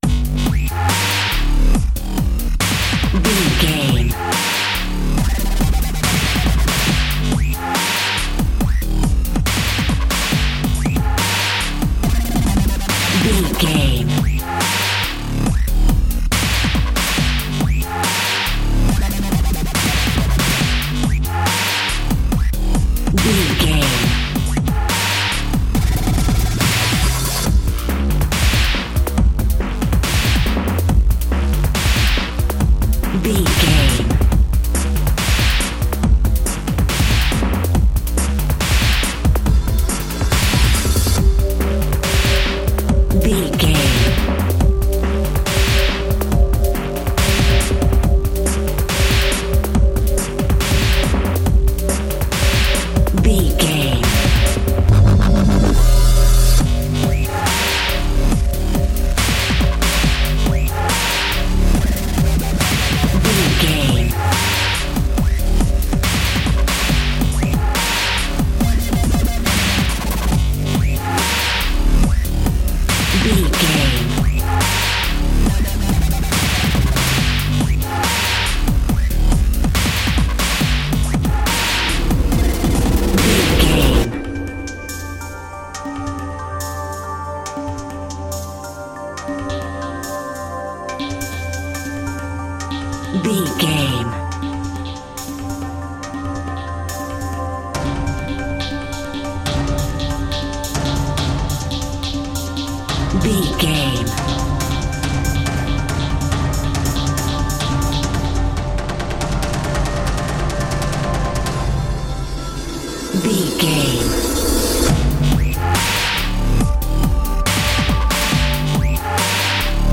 Aeolian/Minor
synthesiser
drum machine
orchestral
orchestral hybrid
dubstep
angry
aggressive
energetic
intense
powerful
strings
drums
bass
synth effects
wobbles
heroic
driving drum beat
epic